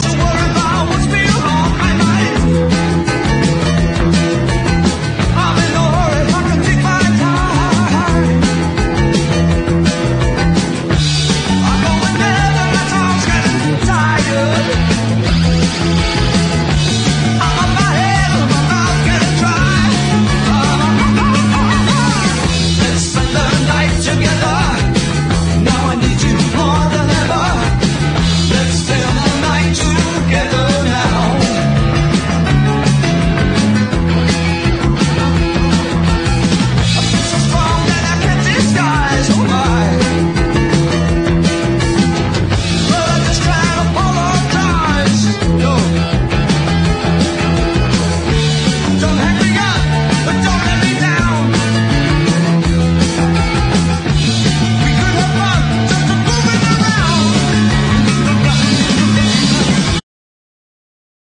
レアな1970年ガレージ/サイケ・ロック！
泣きのメロウ・ロック・バラード